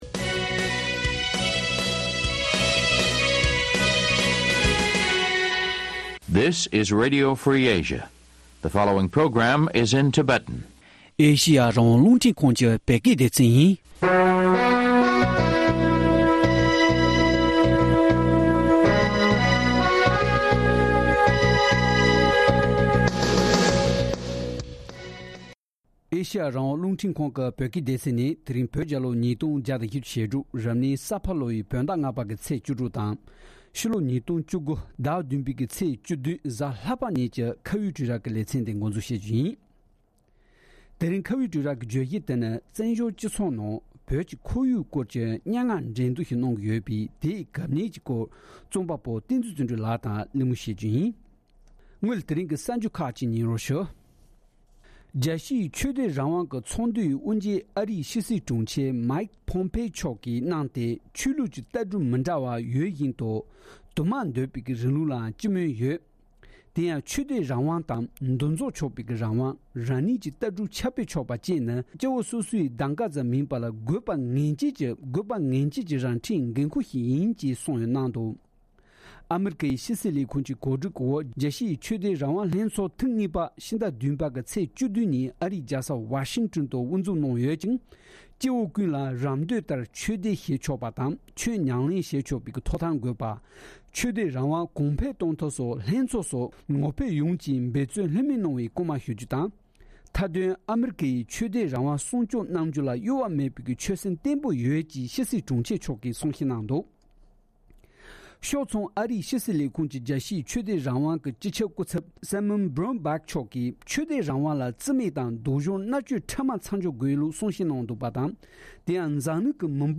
བཅའ་འདྲི་བྱས་པ་ཉན་རོགས་གནང་།།